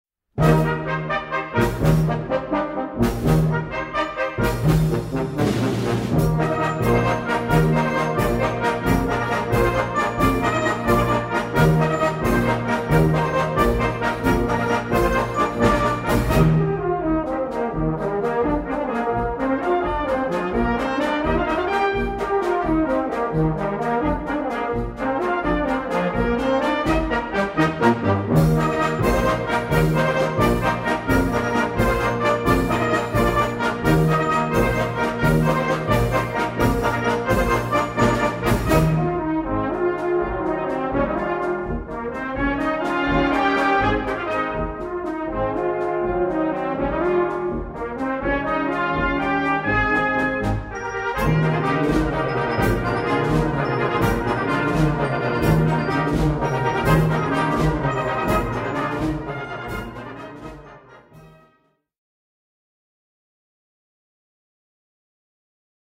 Gattung: Auszüge aus dem Konzertwalzer
Besetzung: Blasorchester